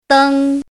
怎么读
dēng